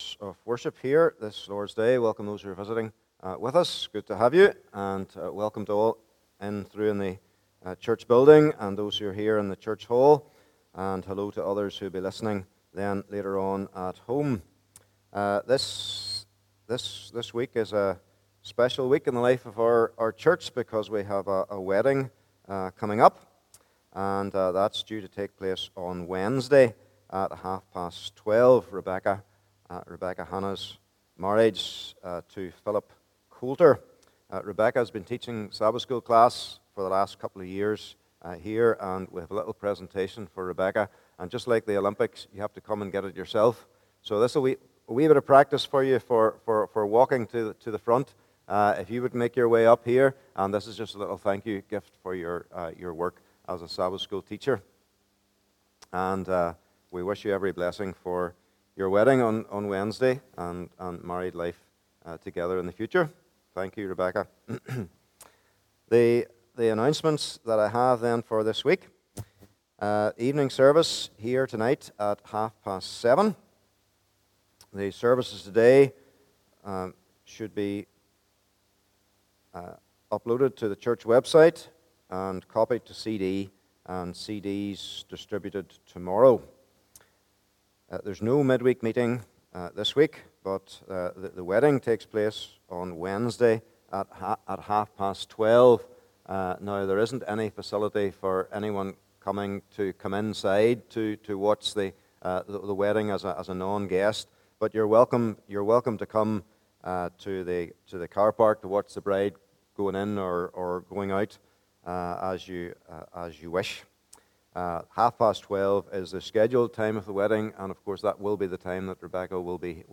Passage: Philippians 2 : 25 -30 Service Type: Morning Service « The LORD is My/Our Salvation and Strength Serving Christ in Thought